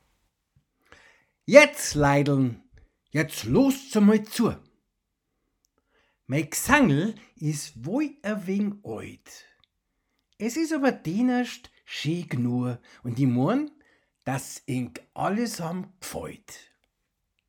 An der Zither